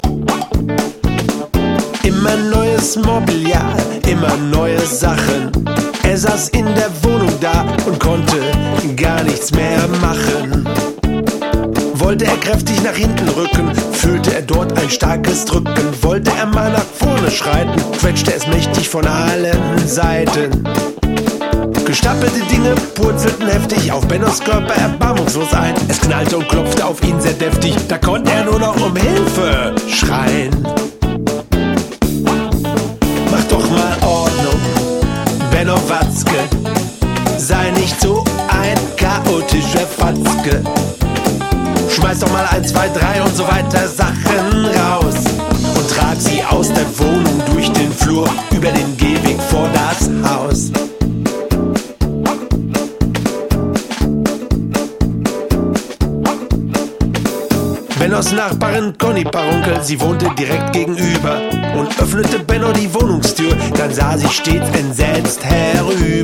Mal swingend, mal rockig, mal Texte für den Kopf,
mal Rhythmen die in die Beine gehen...